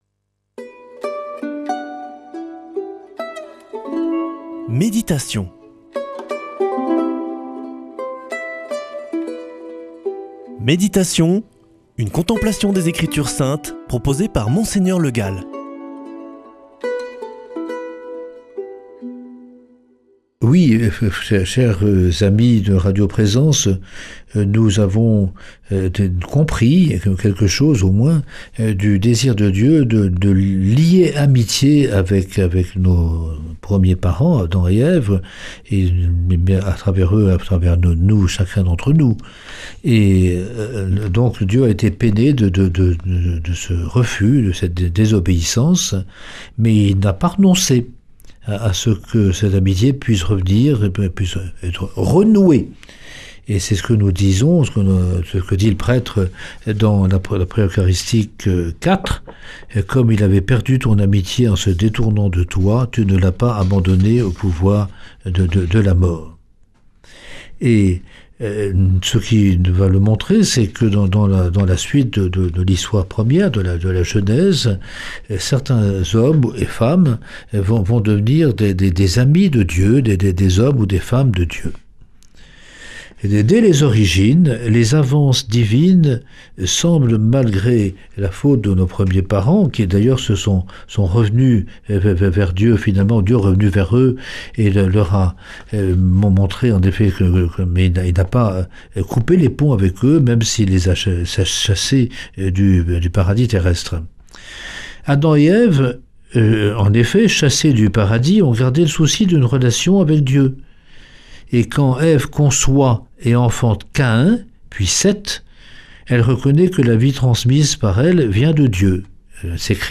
Méditation avec Mgr Le Gall
Une émission présentée par